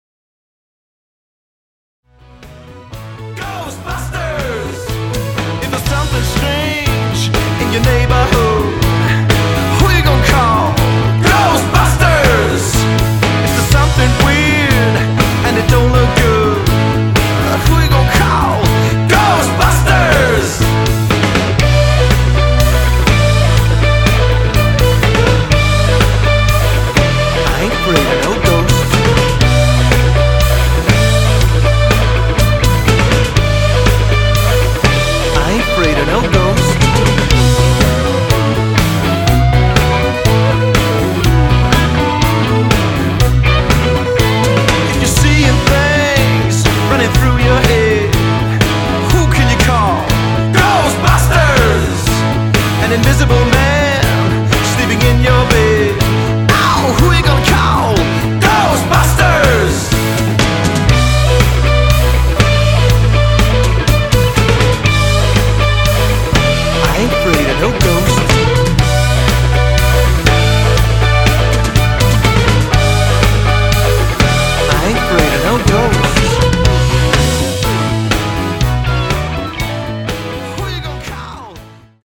Toptjekket fempersoners partyband.
• Coverband